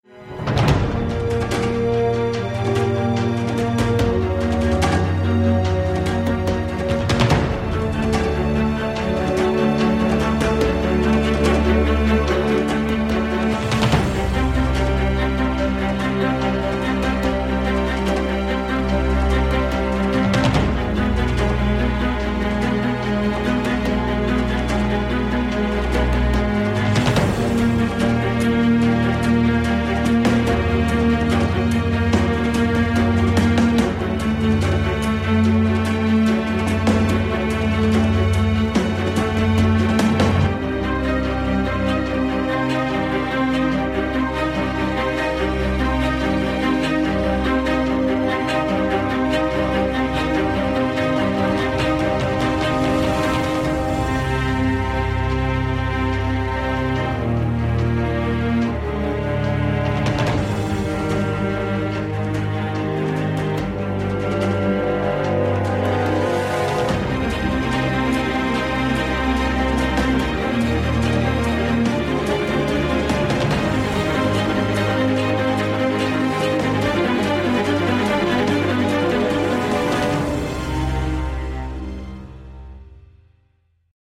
News Report / Jakarta stock exchange walkway collapses